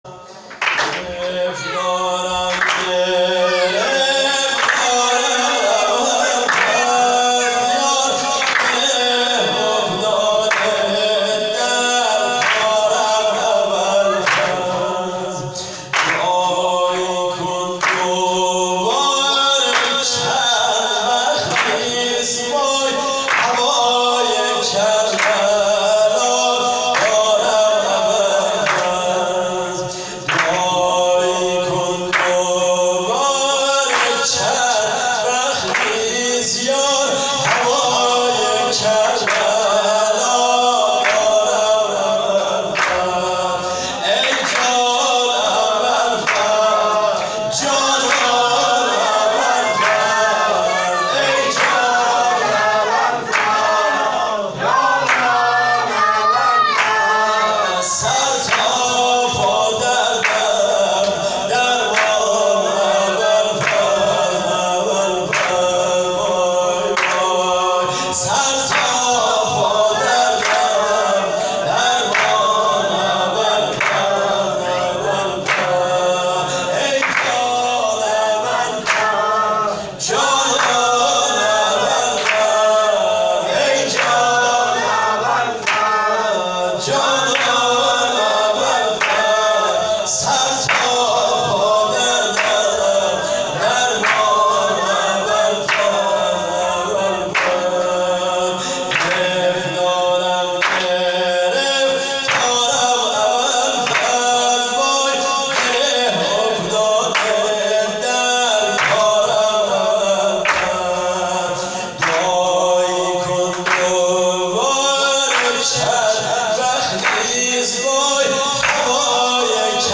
خیمه گاه - هیئت محبان المهدی(عج)آمل - شب میلاد سرداران کربلا_بخش چهارم سرود
هیئت محبان المهدی(عج)آمل